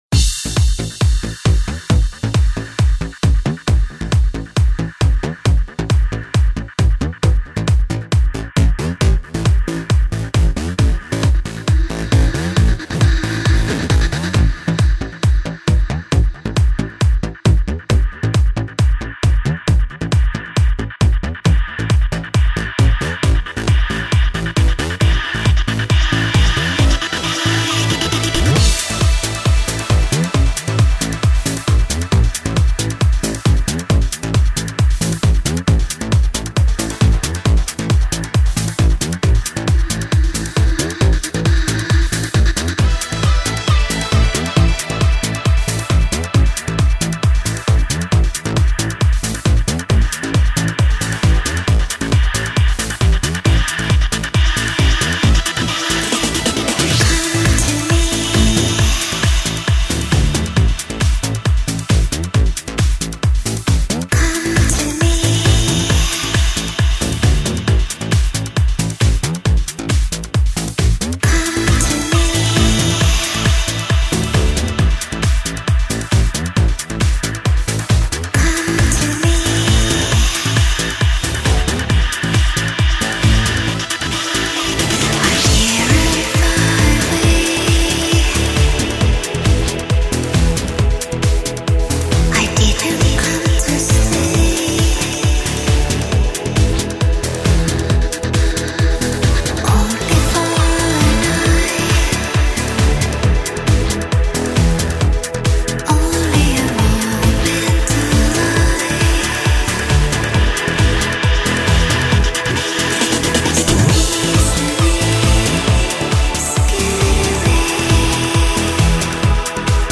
her new polished trance sound